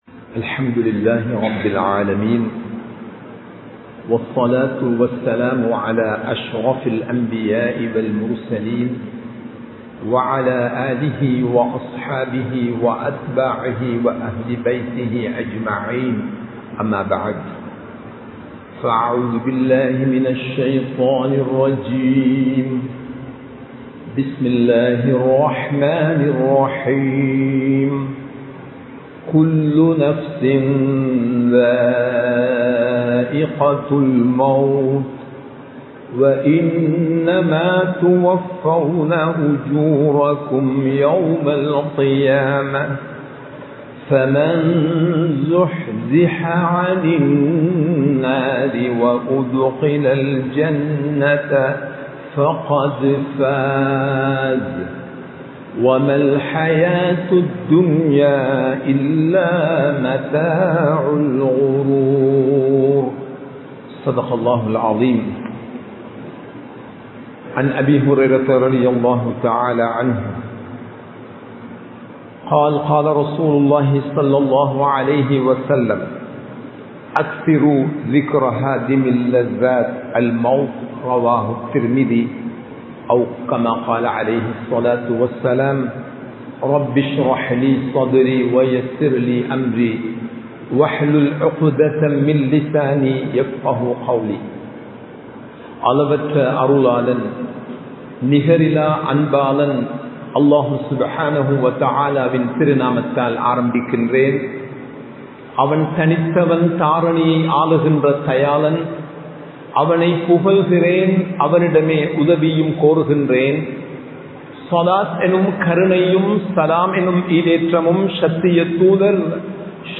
மறக்க முடியாத மரணம் | Audio Bayans | All Ceylon Muslim Youth Community | Addalaichenai